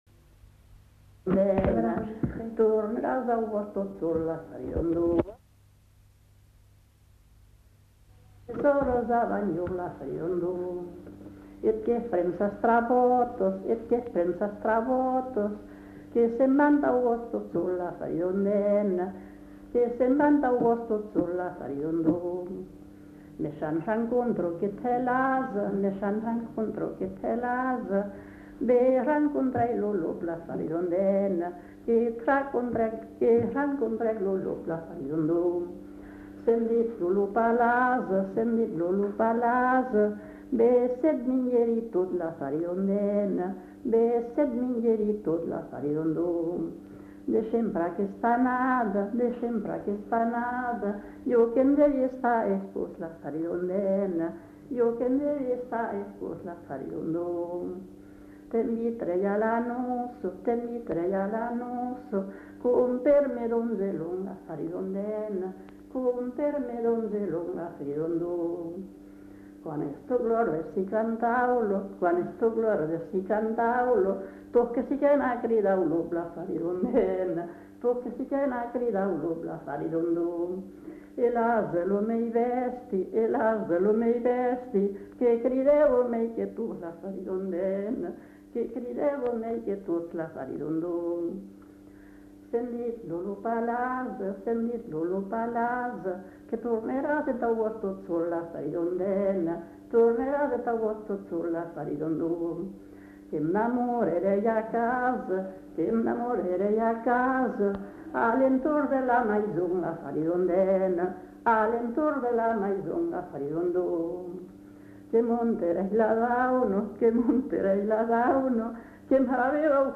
[Brocas. Groupe folklorique] (interprète)
Genre : chant
Effectif : 1
Type de voix : voix de femme
Production du son : chanté
Notes consultables : Le début du chant n'est pas enregistré.